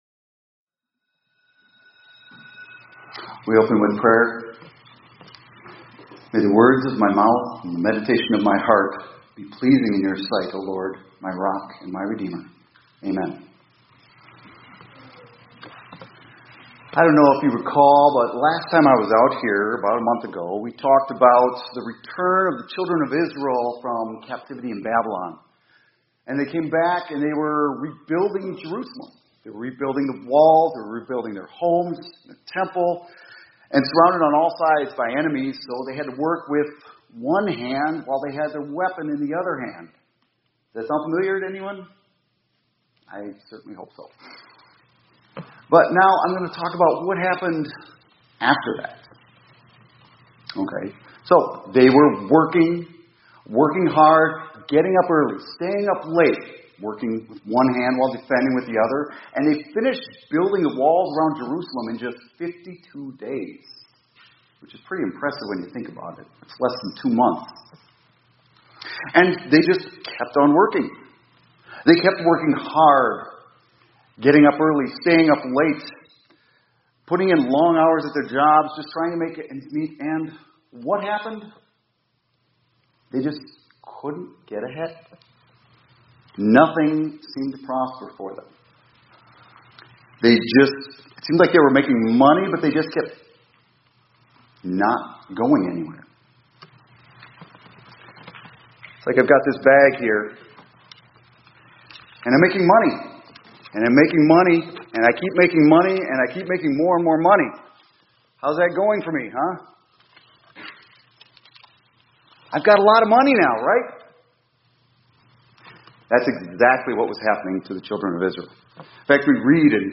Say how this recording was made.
2025 Chapels -